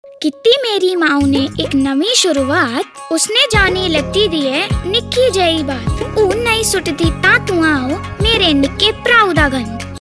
Radio spot Hindi TSC child excreta disposal pit children